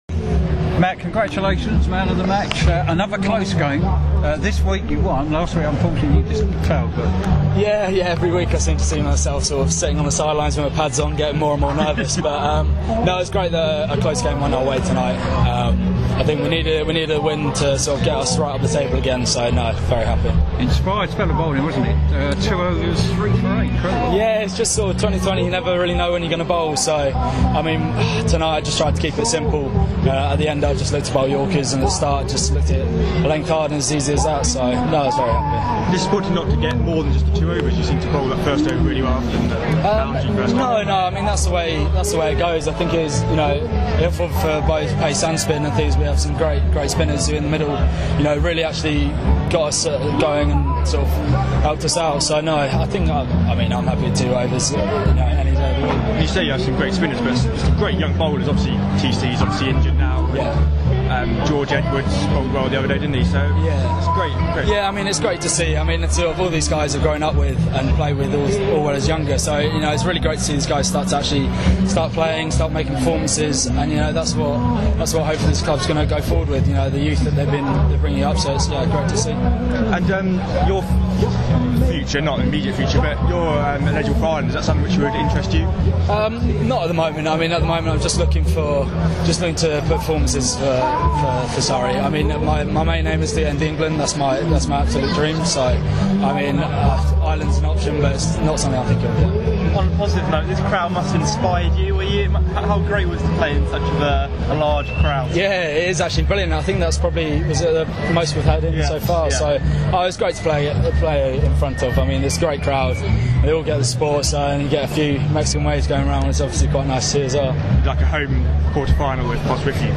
speaking after the sides NatWest T20 Blast victory against Somerset at the Kia Oval